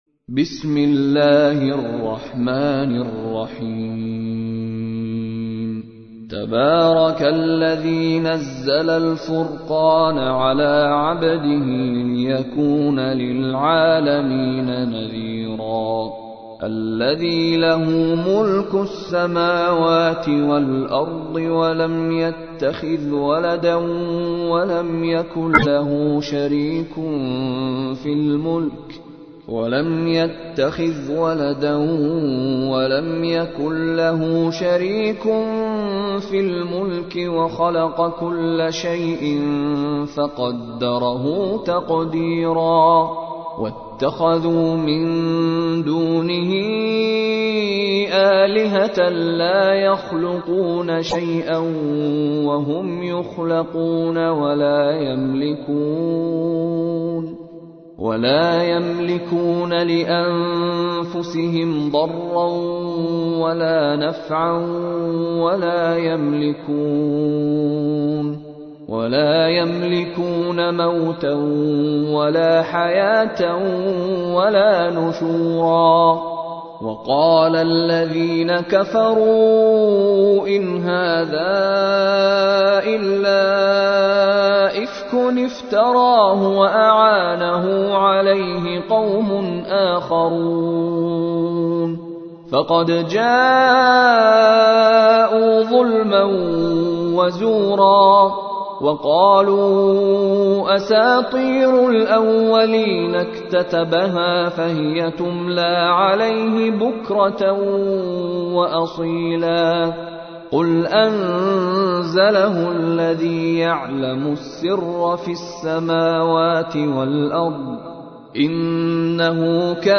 تحميل : 25. سورة الفرقان / القارئ مشاري راشد العفاسي / القرآن الكريم / موقع يا حسين